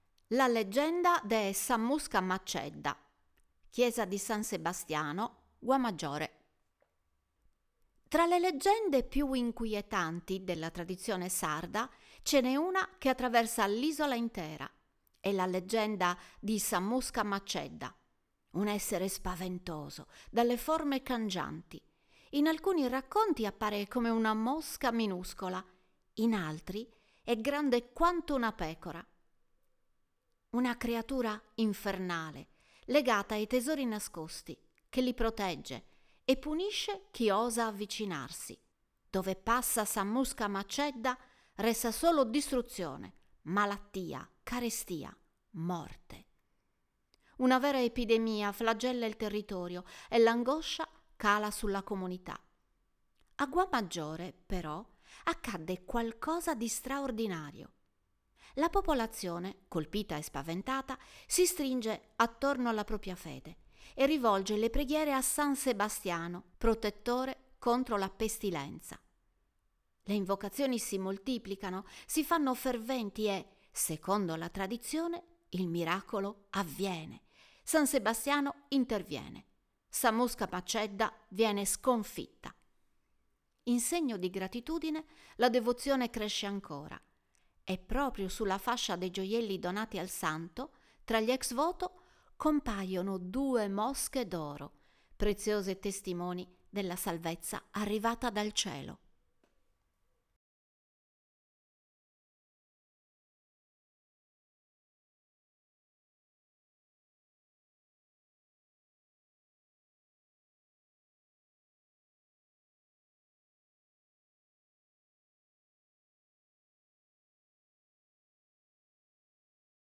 Voce Narrante: